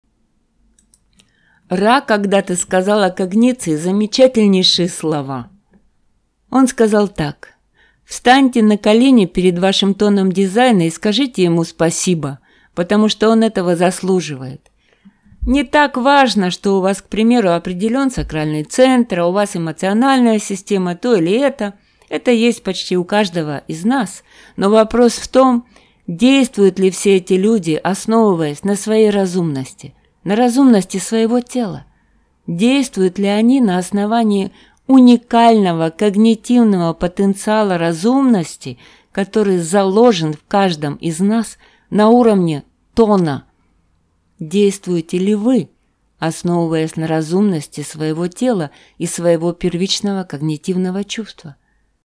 Образцы моего голоса: